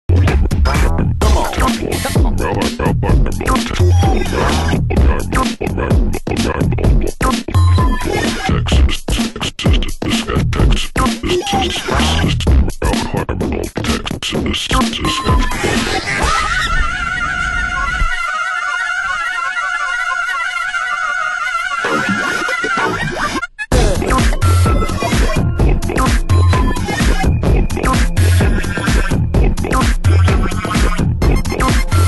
カットアップ手法で唐突な展開が、ユニークで飽きさせないテック・ブレイク！